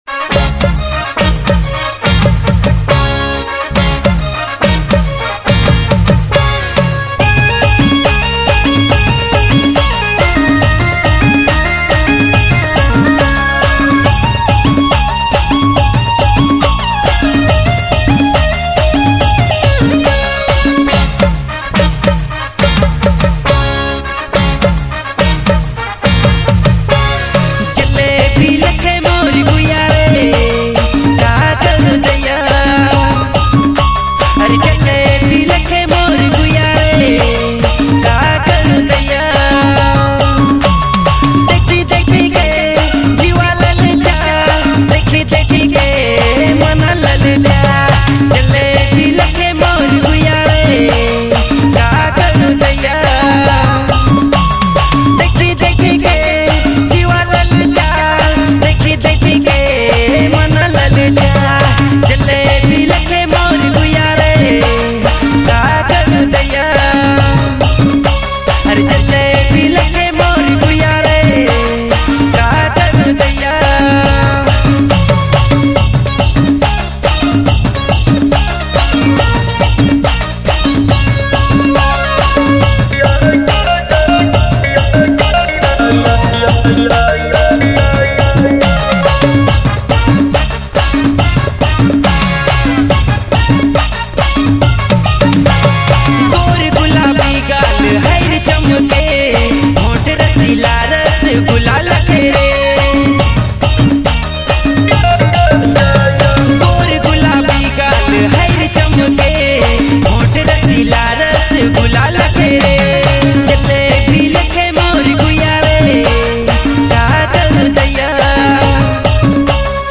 Nagpuri track